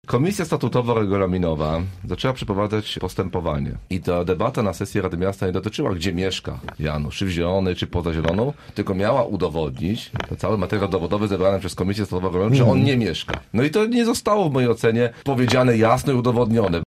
Sprawa jest niejasna – mówił Marcin Pabierowski z PO: